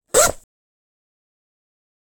フリー効果音：チャック
服やカバンのチャックの音をリアルでサンプリングしてみました！
chuck.mp3